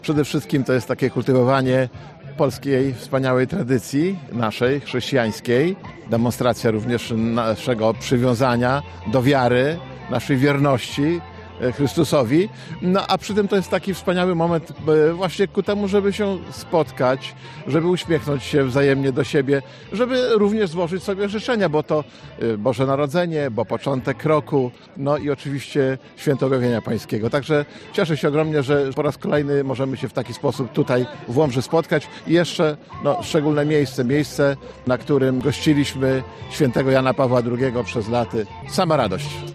Kultywowanie naszej tradycji to dla nas wielka radość dodaje starosta łomżyński, Lech Marek Szabłowski: